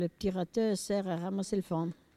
Localisation Barbâtre
Catégorie Locution